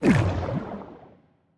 whirlpool_inside.ogg